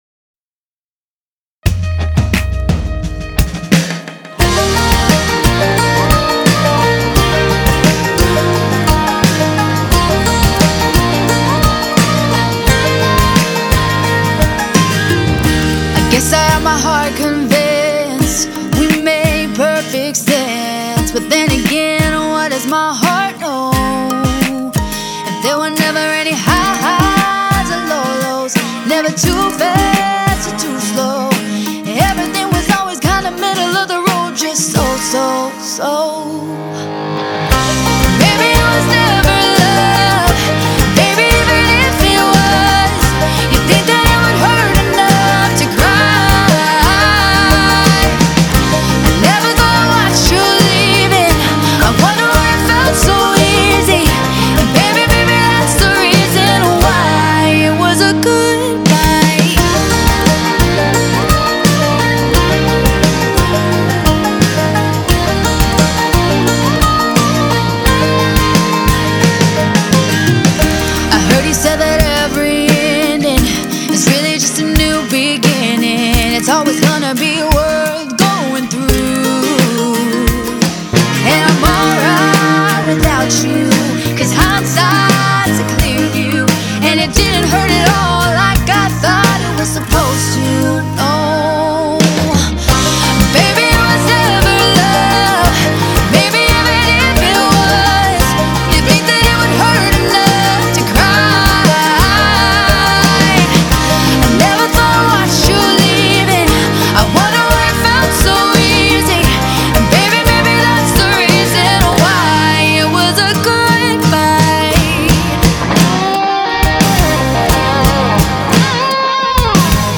‘upbeat’
strong vocals